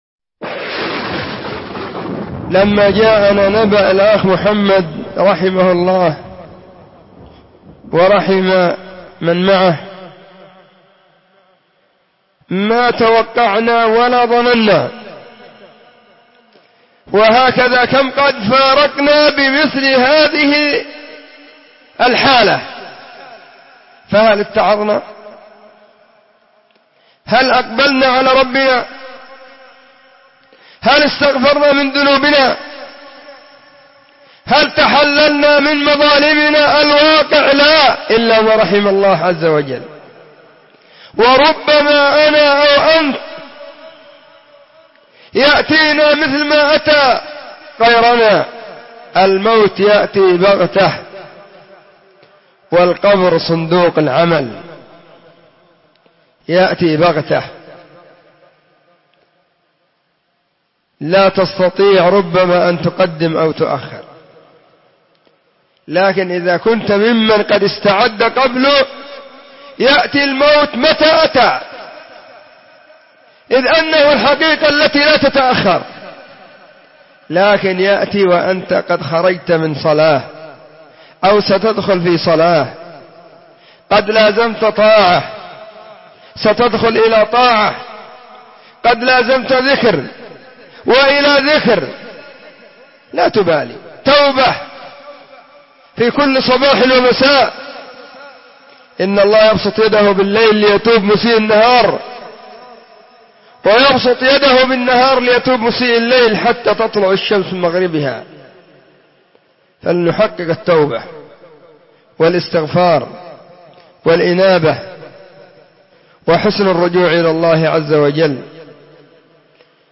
🎙كلمة بعنوان: *🏝نصح وتعزية*